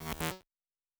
Sci-Fi Sounds / Electric / Glitch 1_09.wav
Glitch 1_09.wav